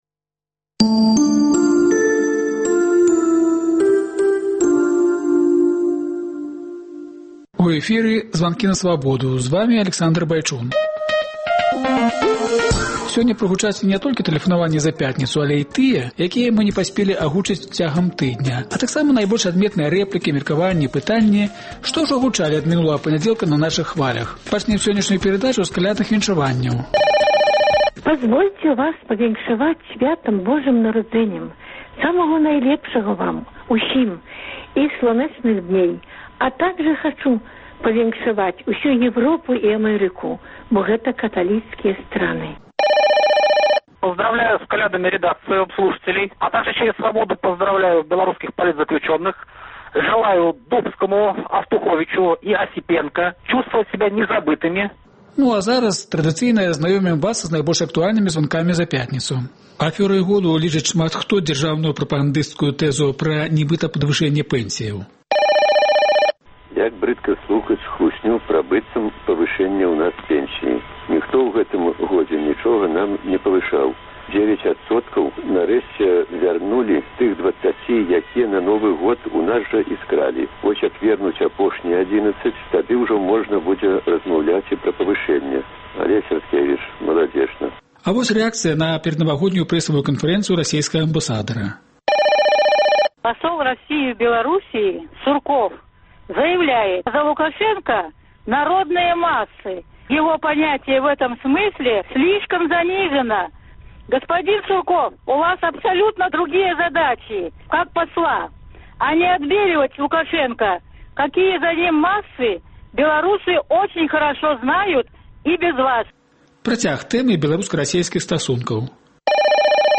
Тыднёвы агляд званкоў ад слухачоў Свабоды